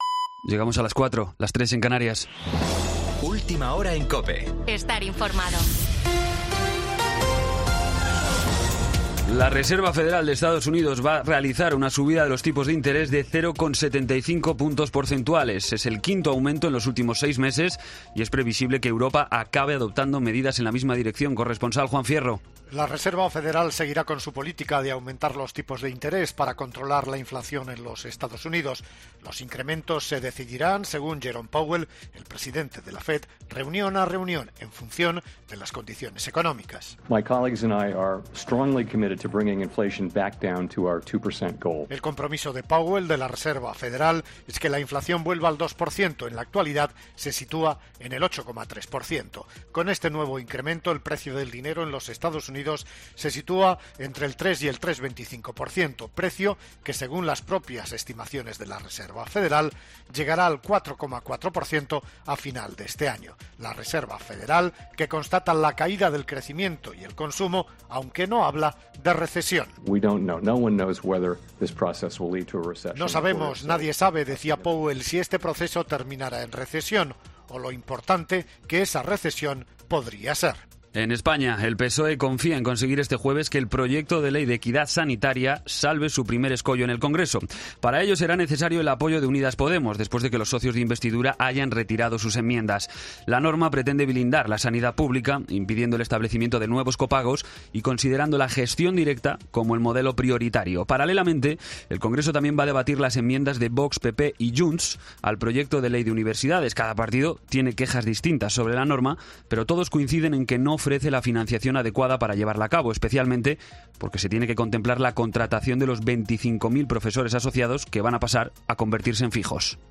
Boletín de noticias COPE del 22 de septiembre a las 04:00 hora
AUDIO: Actualización de noticias Herrera en COPE